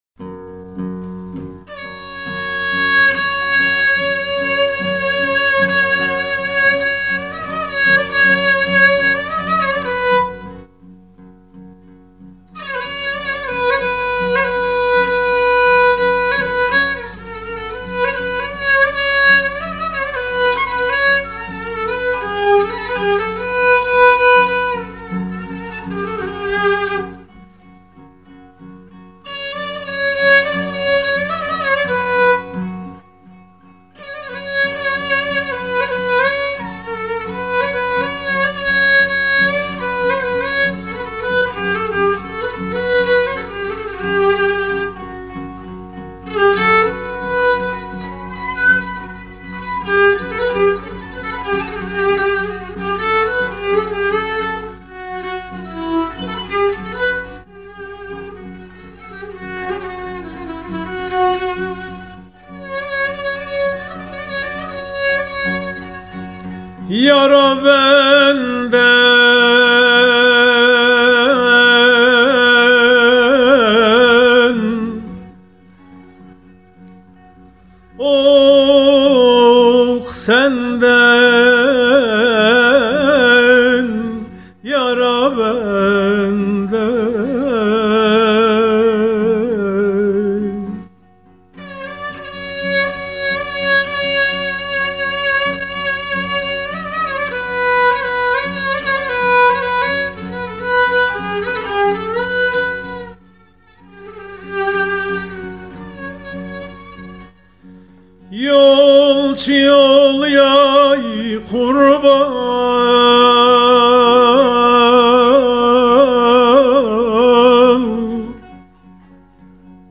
Etiketler: urfa, türkü